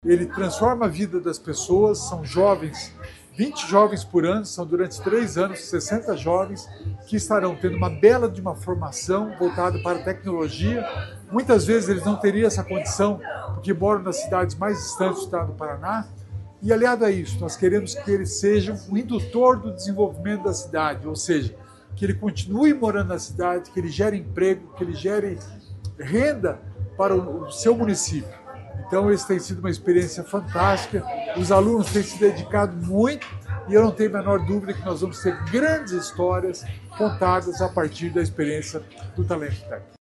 Sonora do secretário da Inovação e Inteligência Artificial, Alex Canziani, sobre o Talento Tech na Ilha das Peças